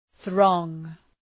Προφορά
{ɵrɔ:ŋ}